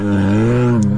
bear-groan-short.mp3